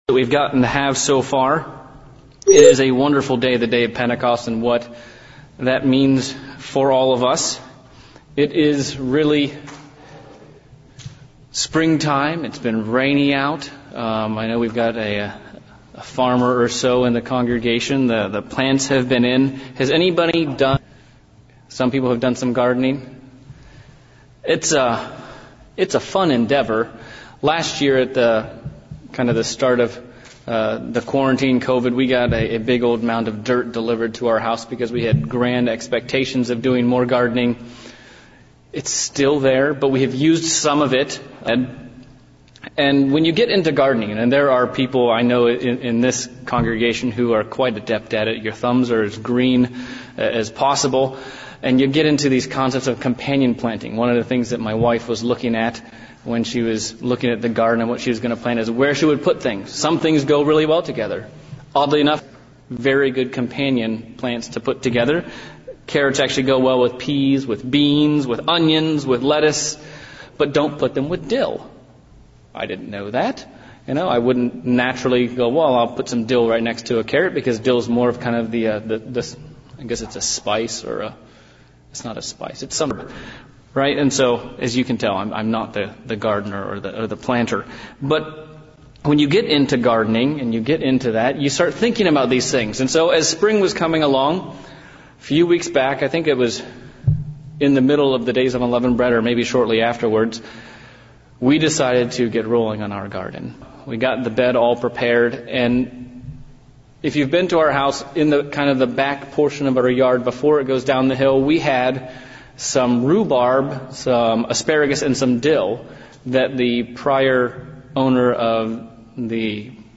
Pentecost PM sermon. How can we use God's spirit to help us thrive and produce Godly fruit